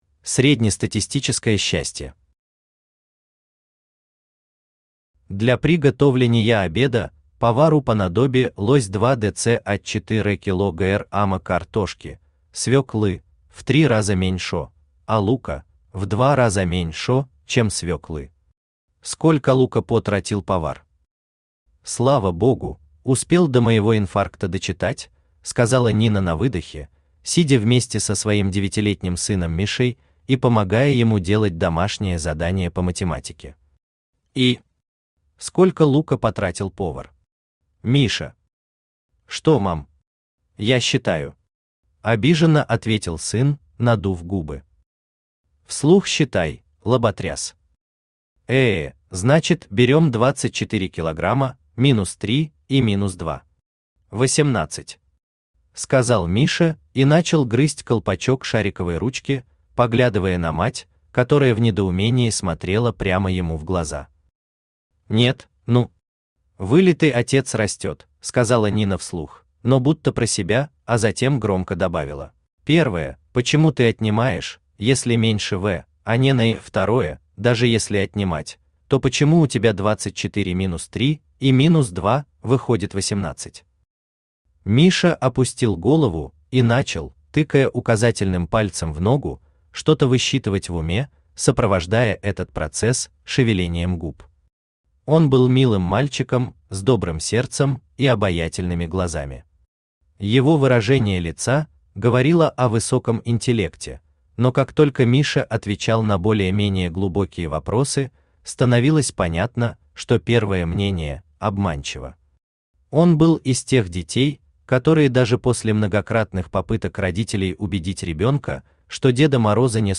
Аудиокнига Антон Антохин – друг Хеопса | Библиотека аудиокниг
Aудиокнига Антон Антохин – друг Хеопса Автор Мирон Воробьёв Читает аудиокнигу Авточтец ЛитРес.